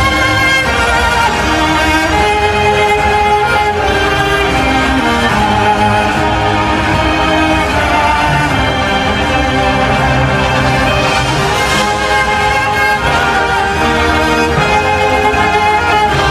Música Clasica